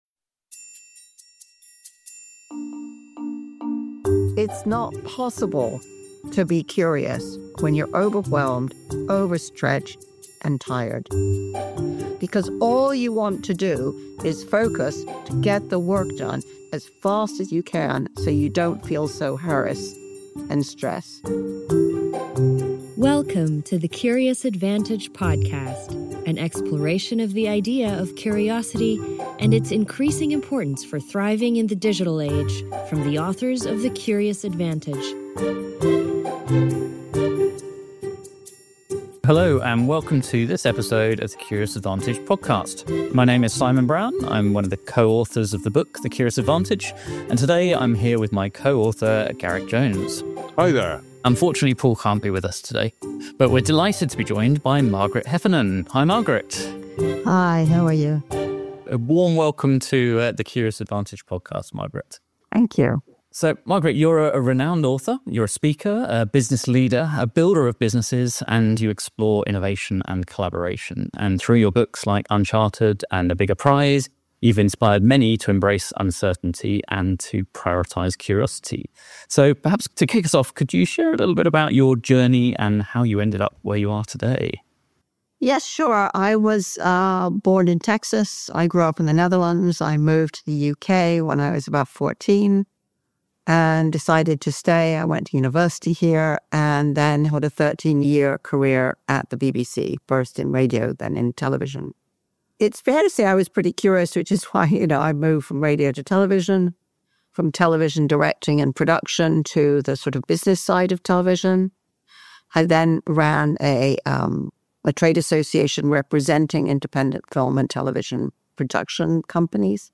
speak with renowned author and speaker Margaret Heffernan about curiosity, leadership, and organizational culture